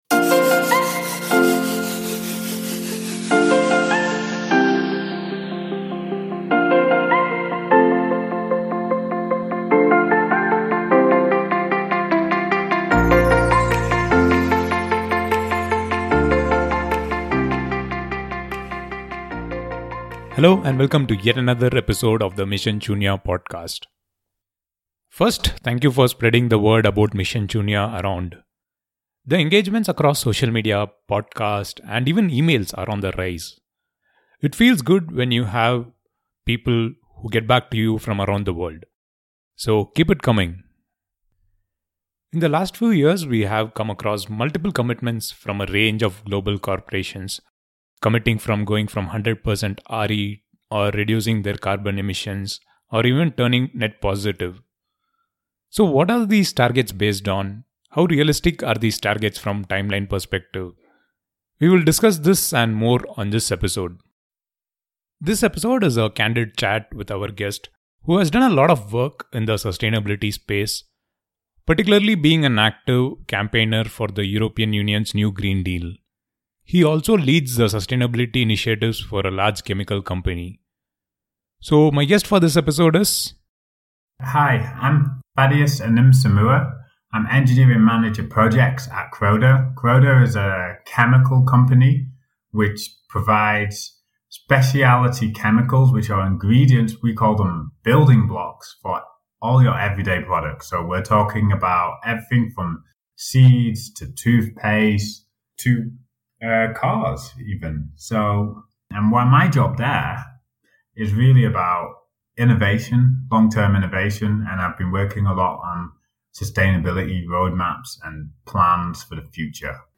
Conversation on Zero Toxicity, Corporate Climate Actions, EU Green Deal & more